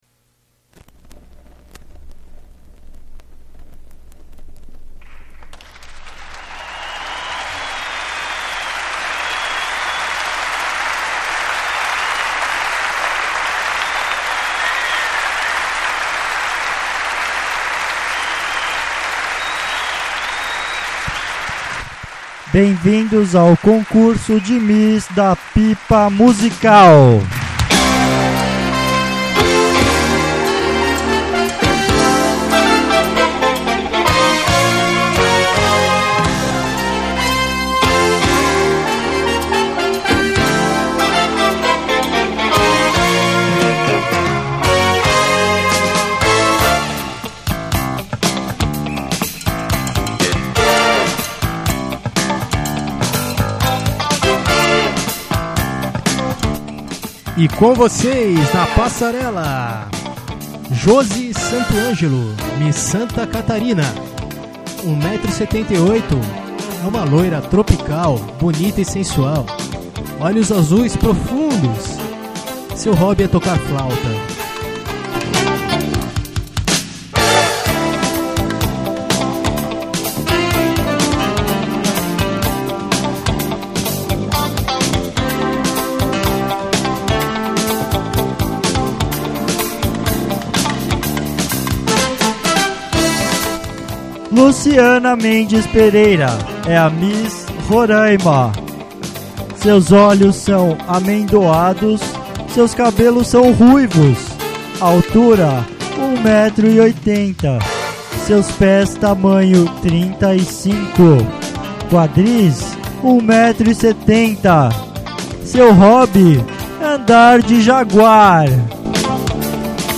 Esse programa foi feito também com apenas 1 (hum) microfone, causando certo embaraço em alguns instantes.